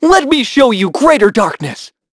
Evan-Vox_Skill4_b.wav